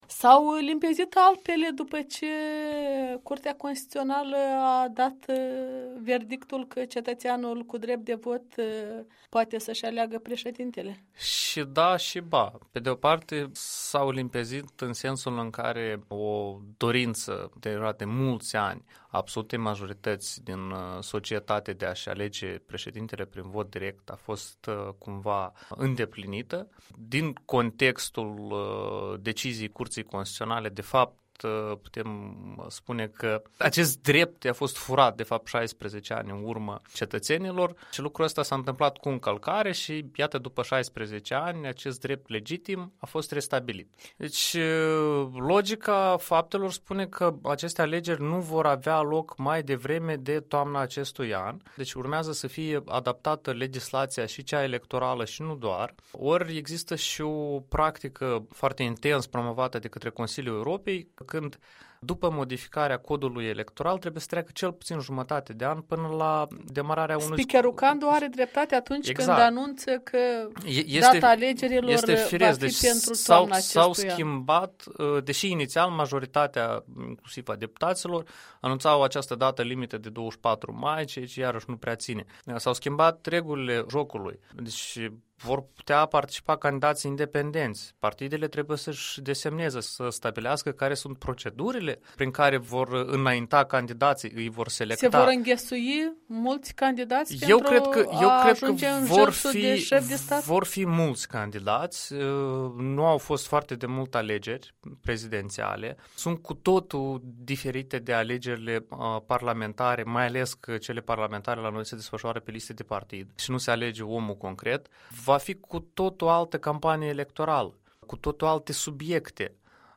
Interviu cu un activist civic.